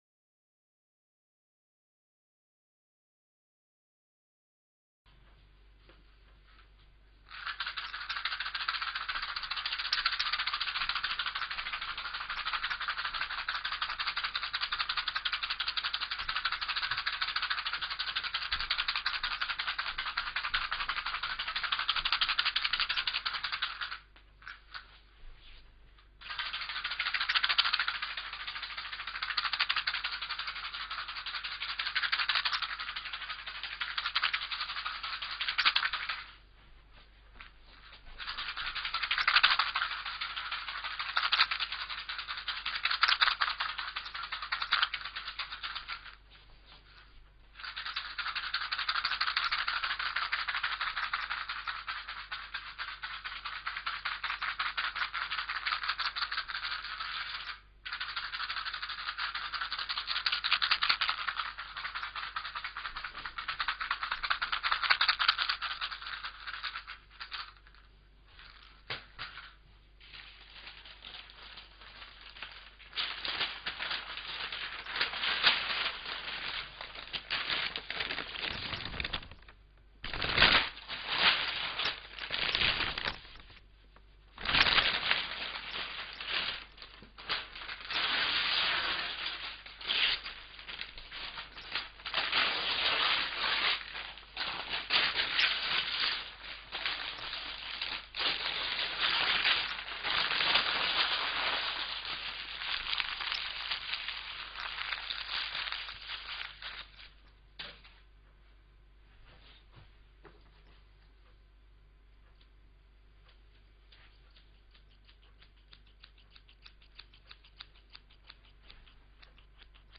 verschiedene Effekte
holophonisch_verschiedene_effekte.mp3